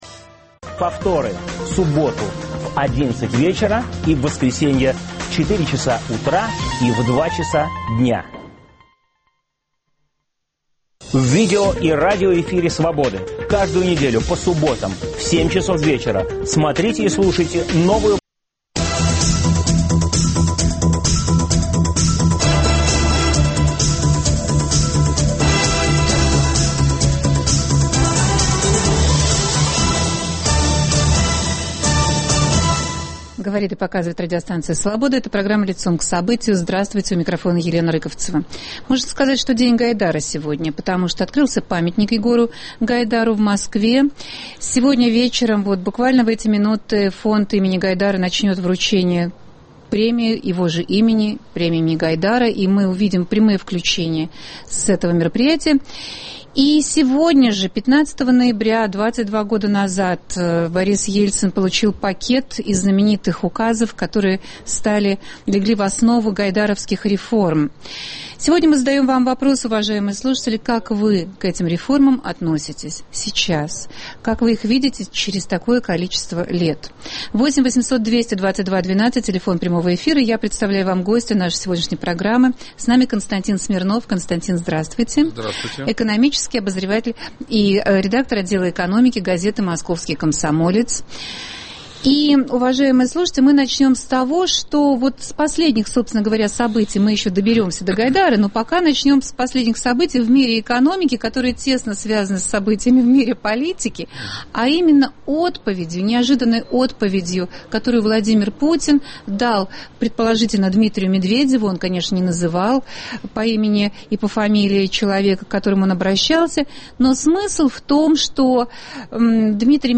В программе обсуждаем причины, по которым Владимир Путин решил вернуть силовикам расследование налоговых преступлений вопреки позиции Дмитрия Медведева, мы также отмечаем очередную годовщину "рыночной экономики": 15 ноября 1991 года командой Гайдара был подготовлен проект Указа о начале реформ в РФ. Сегодня же Фонд имени Гайдара вручает ежегодную премию в области экономики - вас ждут прямые включения с этой церемонии.